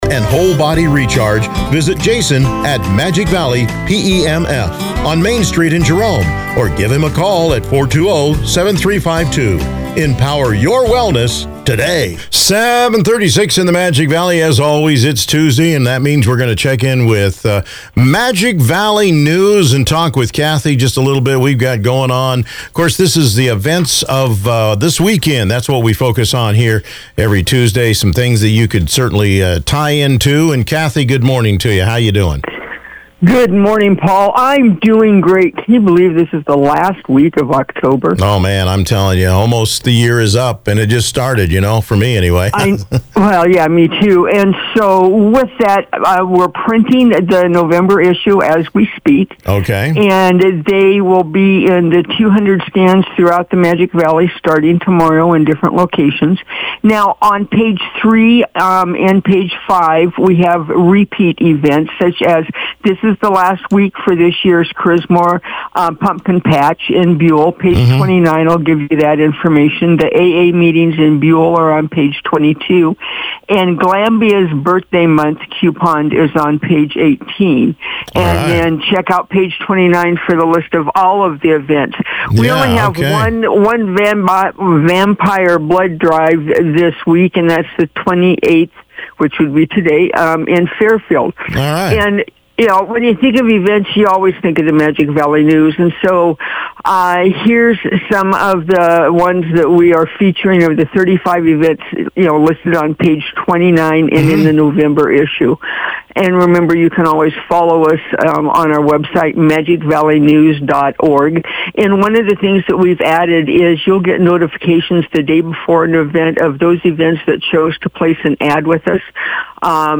Radio Chats